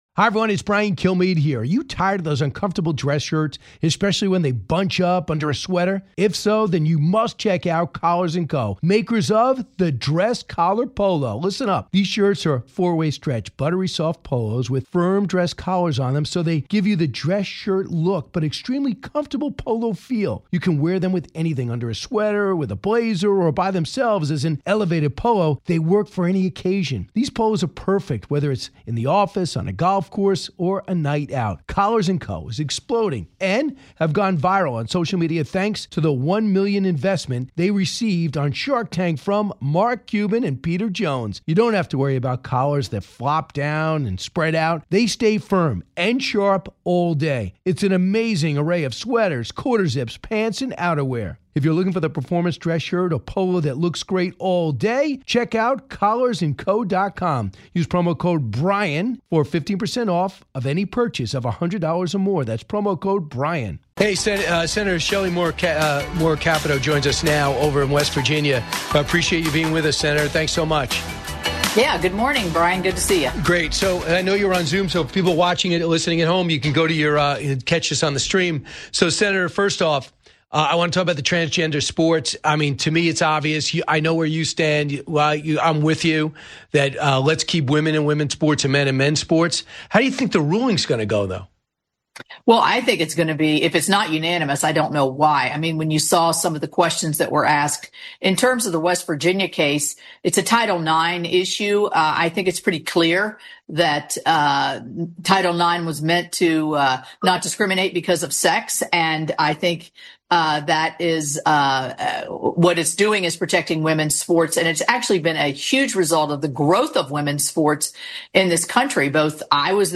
The Truth Network Radio